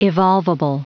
Prononciation du mot evolvable en anglais (fichier audio)